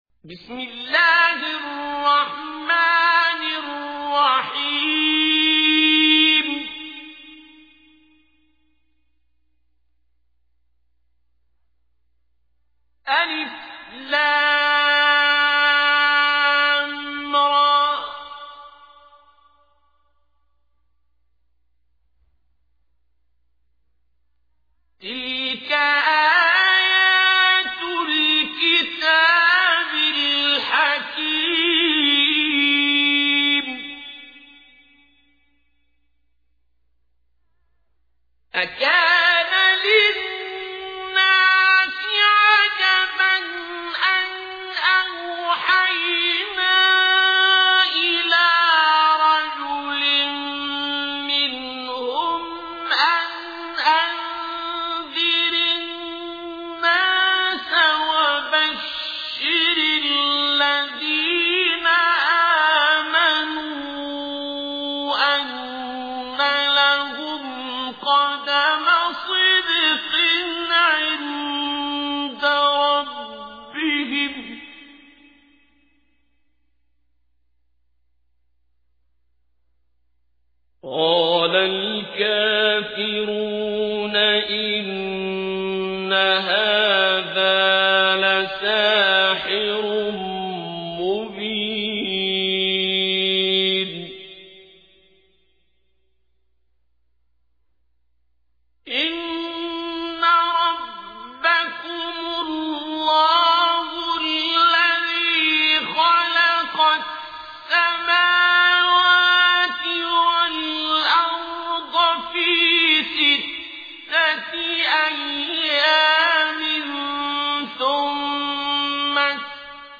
تحميل : 10. سورة يونس / القارئ عبد الباسط عبد الصمد / القرآن الكريم / موقع يا حسين